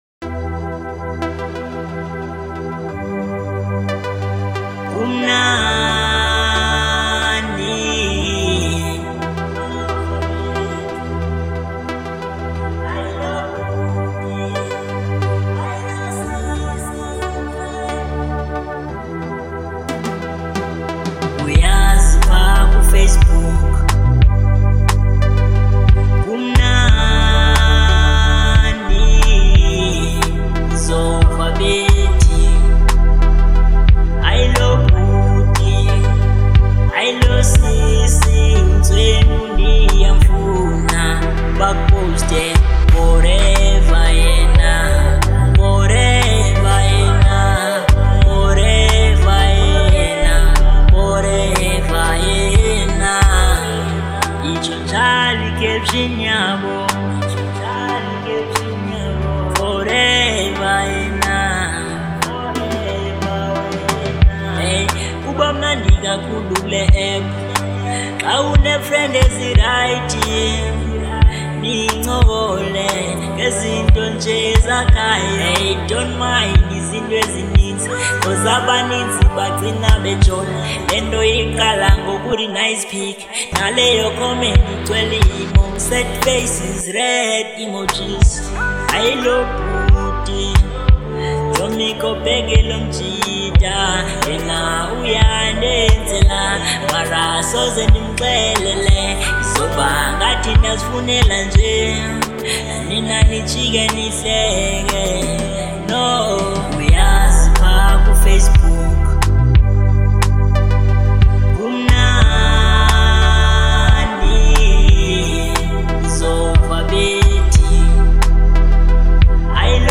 03:46 Genre : Afro Pop Size